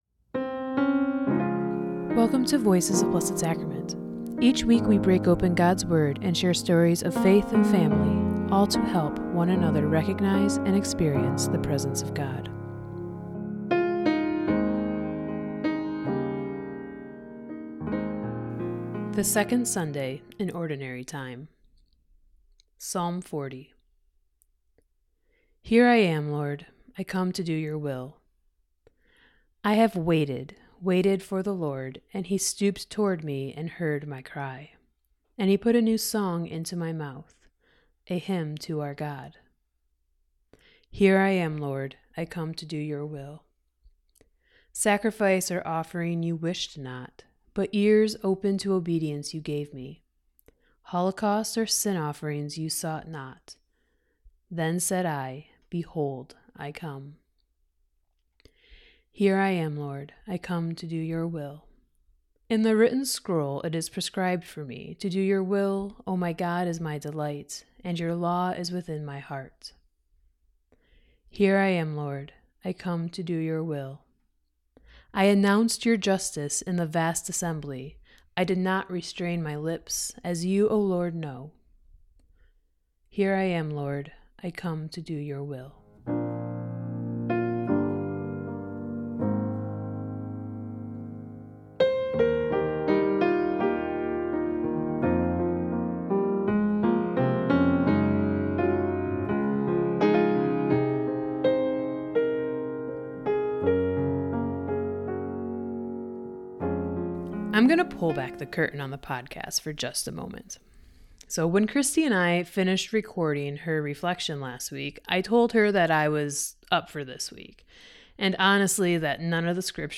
From prayers that sound like “What the heck, God?” to quieter moments of gratitude, this homily reminds us that every honest prayer counts. The psalms don’t ask for polished performances or grand sacrifices; they invite us to listen, to love, and to live lives shaped by obedience and compassion.